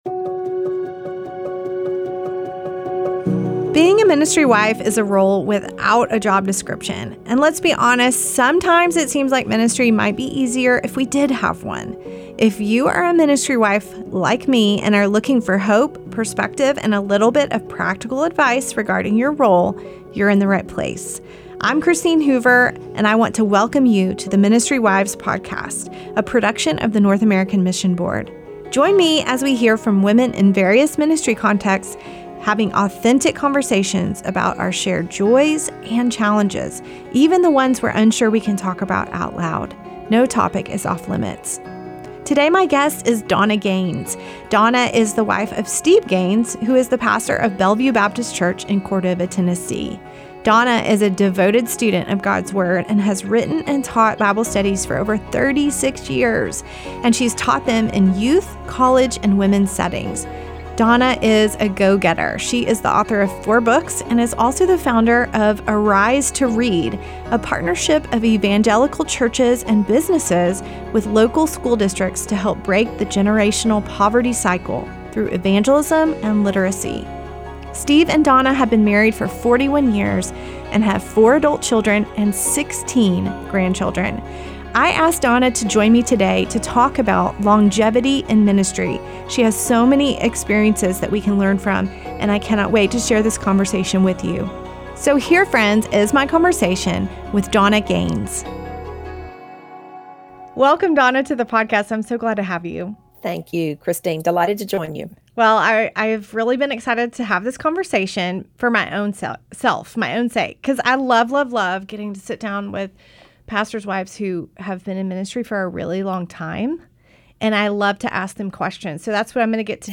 Longevity in Ministry (an interview